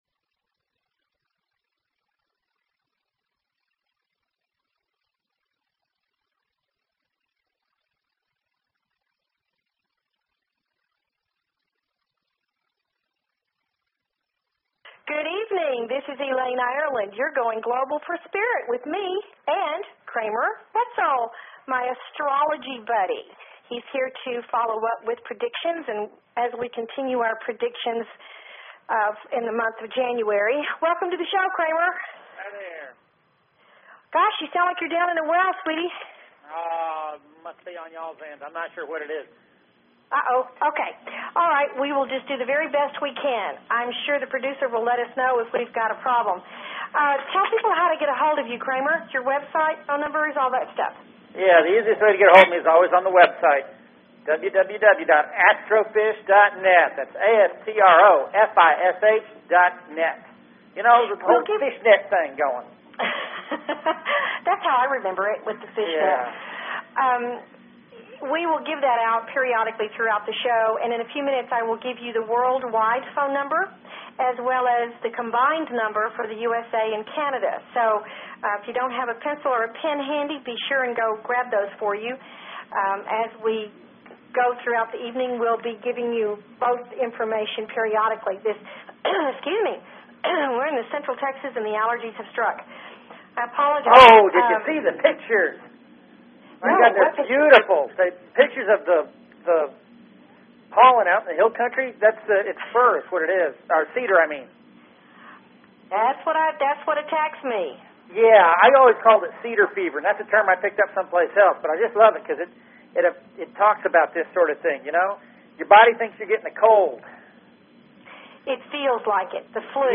Talk Show Episode, Audio Podcast, Going_Global_for_Spirit and Courtesy of BBS Radio on , show guests , about , categorized as
They invite you to call in with your questions and comments about everything metaphysical and spiritual!"